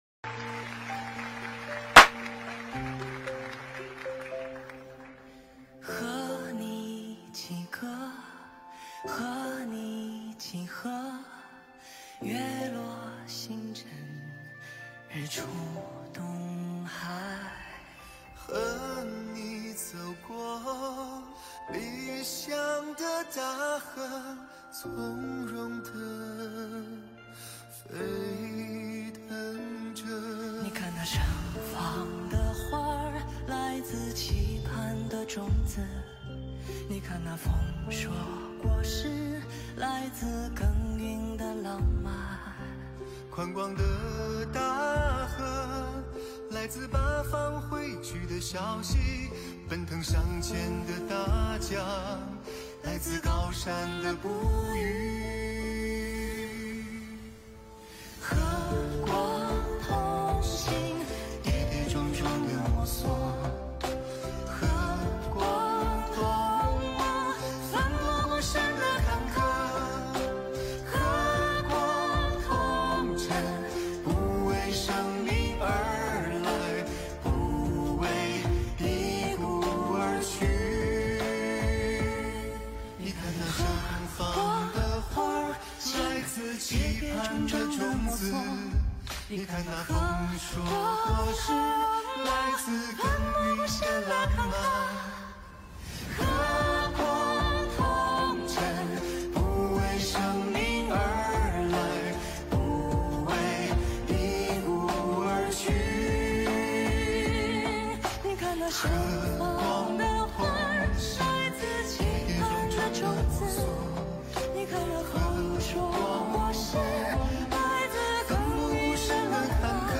音频：温哥华观音堂喜迎兔年春节团拜会～素饺子宴！2023年